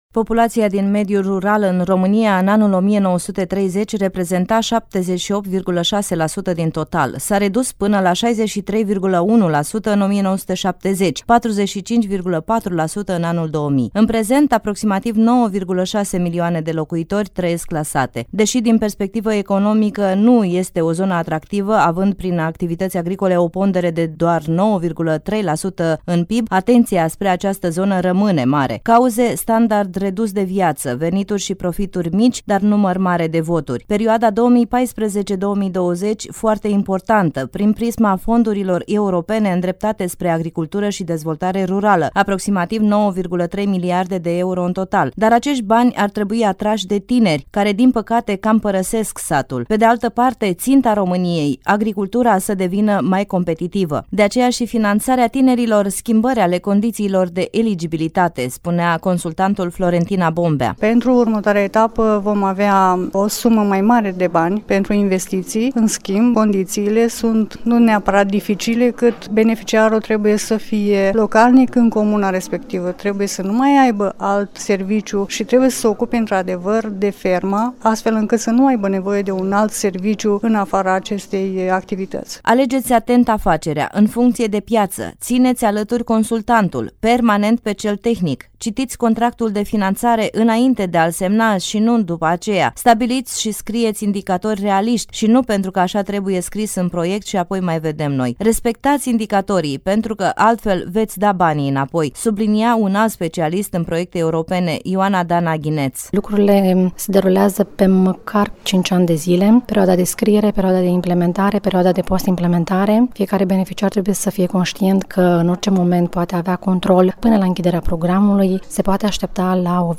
Astăzi a fost difuzată prima producţie radio din cadrul Campaniei “TÂNĂR FERMIER ÎN ROMÂNIA”.